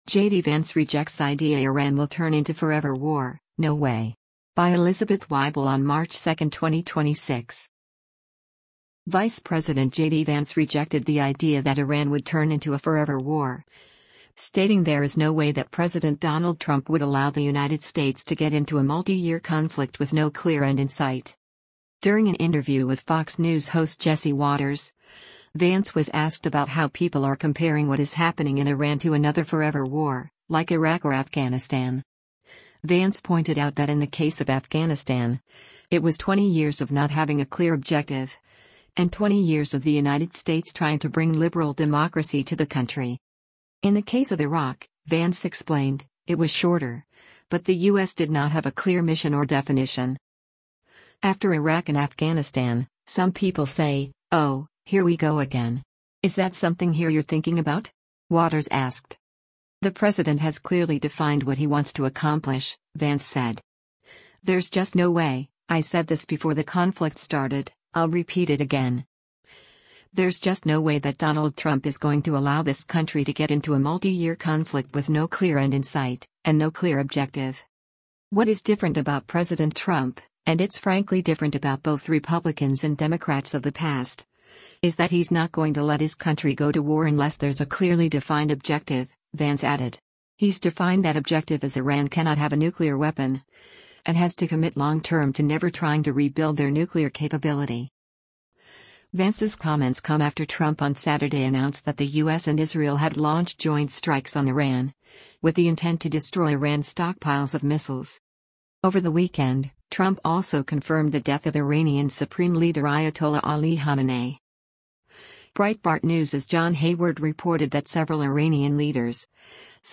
During an interview with Fox News host Jesse Watters, Vance was asked about how people are comparing what is happening in Iran to another forever war, like Iraq or Afghanistan.